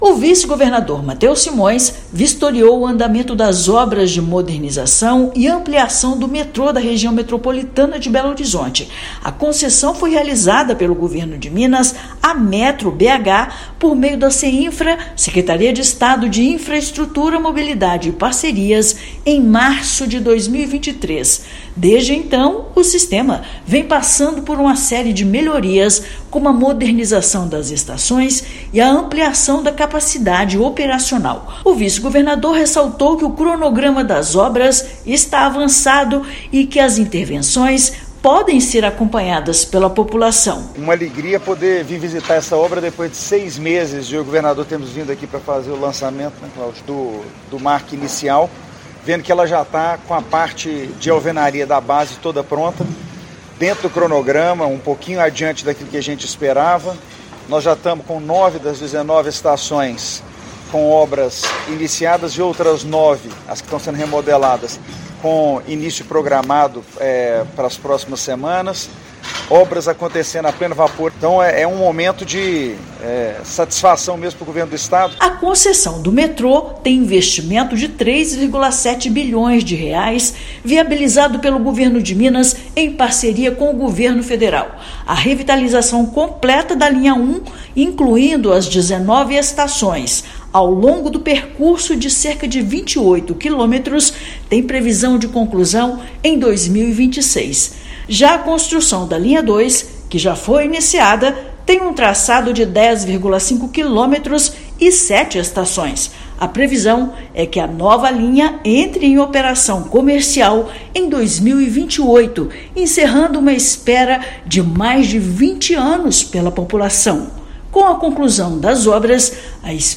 Conclusão da ampliação da Linha 1 vai permitir a abertura de uma nova estação no ano que vem, enquanto a construção da Linha 2 avança nas estações Amazonas e Nova Suíça. Ouça matéria de rádio.